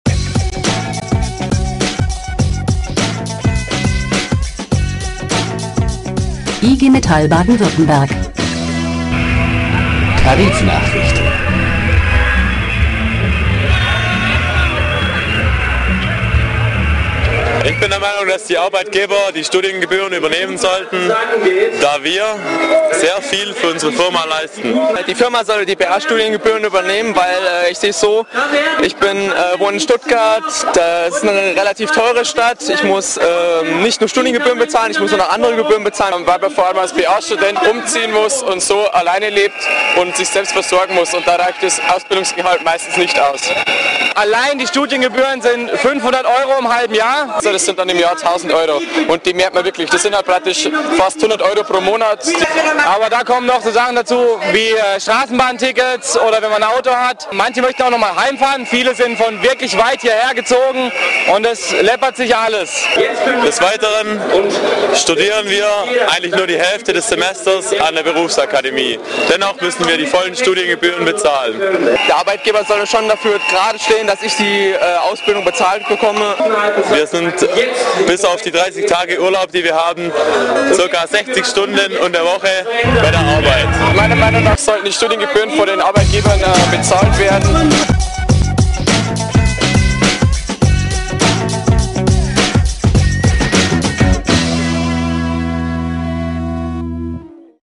Warum die Studiengebühren für BA-Studierende vom Ausbildungsbetrieb getragen werden müssen, erläutern einige Betroffene am Rande der IG Metall-Jugend-Kundgebung in Ludwigsburg am 16. April '07.